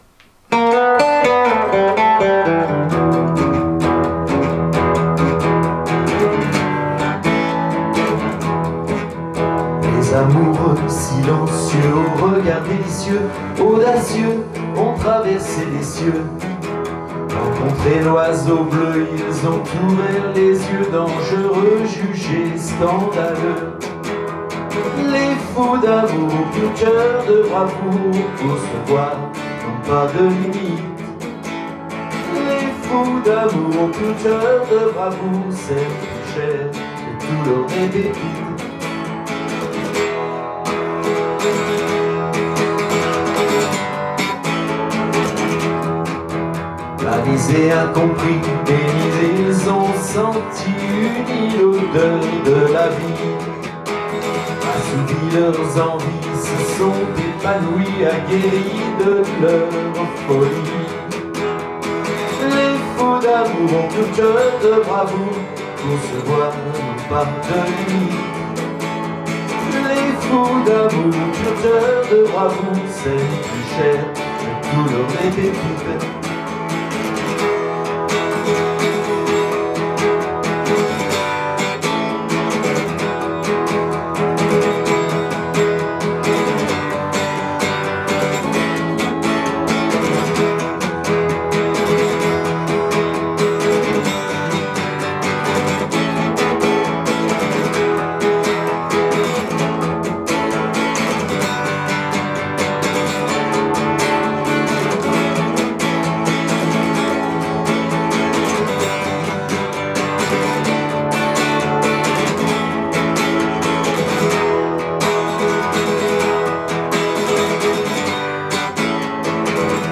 Les amoureux au cœur pur accèdent au rock sans limites.